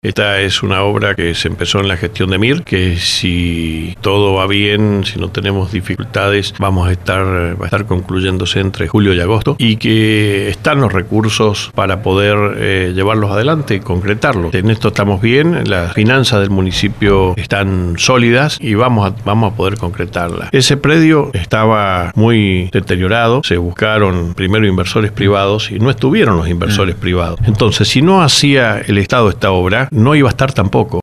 La falta de acompañamiento del Estado nacional, su economía, la autonomía municipal y la modernización del Estado fueron algunos de los temas sobre los que habló Omar Félix -intendente de San Rafael- en LV18, apenas unas horas más tarde de su discurso de apertura de sesiones ordinarias del Concejo Deliberante.